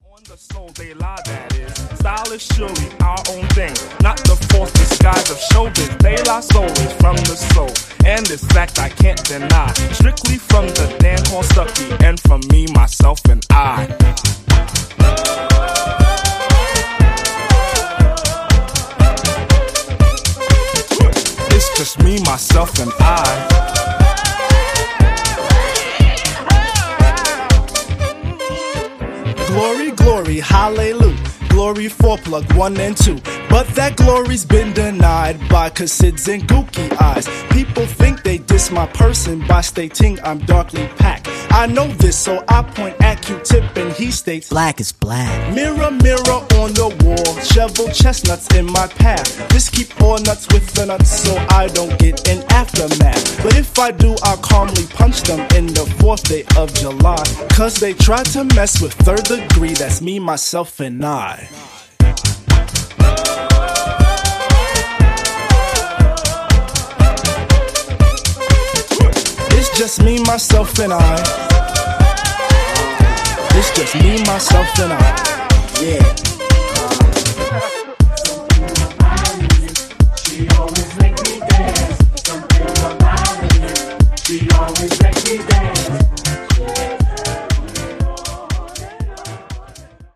a more jackin’ bumpy groove